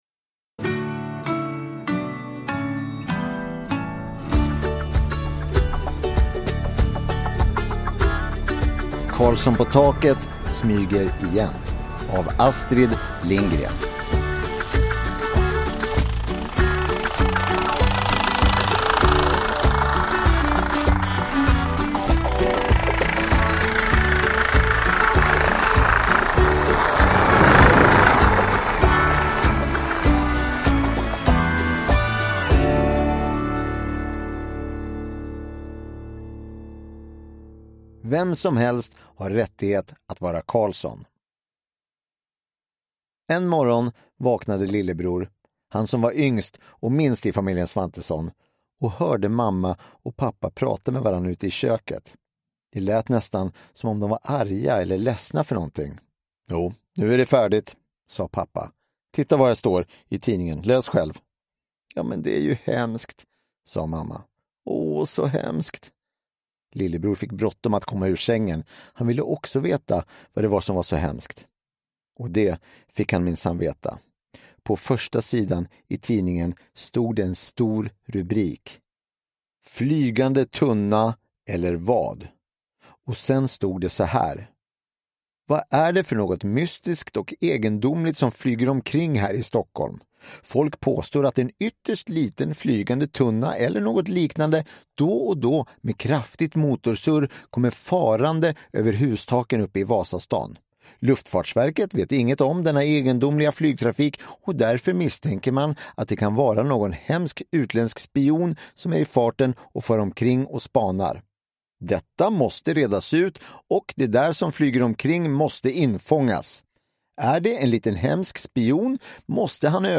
Karlsson på taket smyger igen – Ljudbok